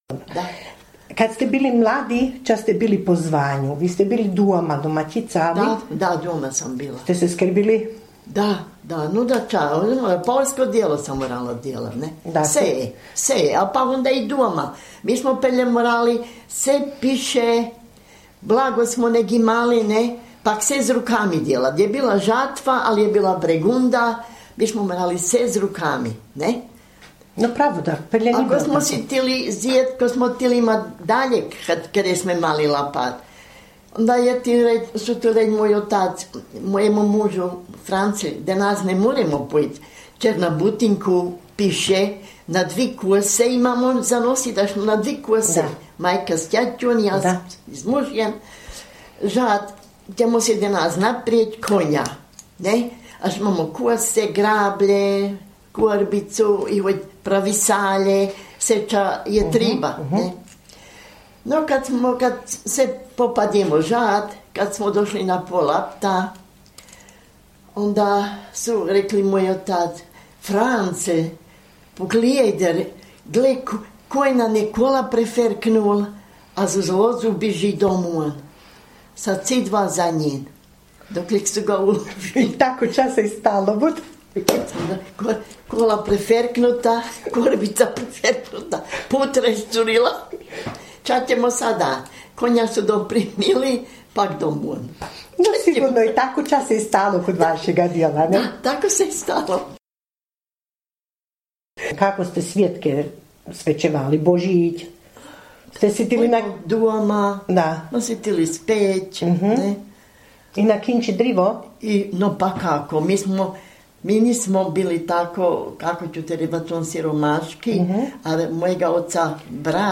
Mjenovo – Govor
26_Mjenovo_govor.mp3